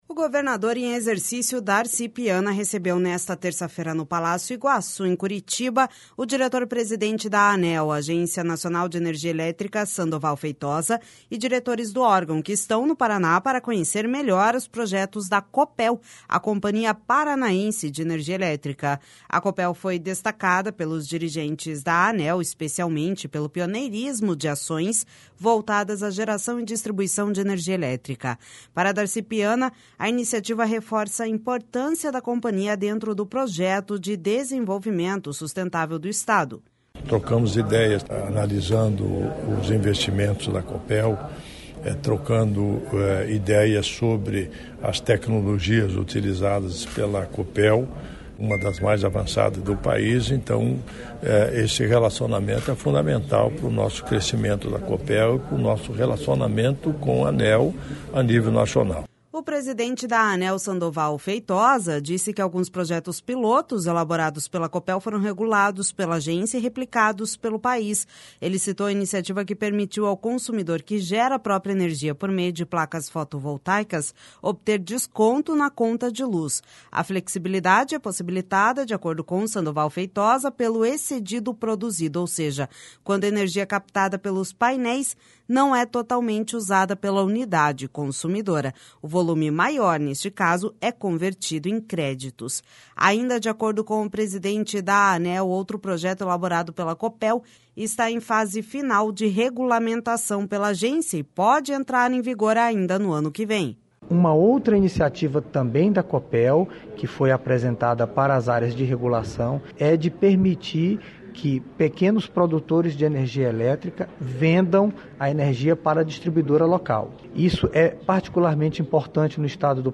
A Copel foi destacada pelos dirigentes da Aneel, especialmente pelo pioneirismo de ações voltadas à geração e distribuição de energia elétrica. Para Darci Piana, a iniciativa reforça a importância da Companhia dentro do projeto de desenvolvimento sustentável do Estado.// SONORA DARCI PIANA//O presidente da Aneel, Sandoval Feitosa, disse que alguns projetos-pilotos elaborados pela Copel foram regulados pela agência e replicados pelo País.
Ainda de acordo com o presidente da Aneel, outro projeto elaborado pela Copel está em fase final de regulamentação pela agência e pode entrar em vigor ainda no ano que vem.// SONORA SANDOVAL FEITOSA//A proposta é que os pequenos produtores do Paraná vendam até 10% da energia captada nas propriedades para a Copel Distribuição.